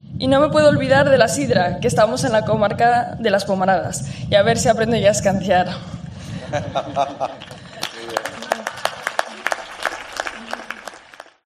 Leonor, además, prometió ante los asturianos aprender a echar la sidra de la forma tradicional: "¡A ver si aprendo a escanciar!", bromeó entre los aplausos y las risas de los presentes.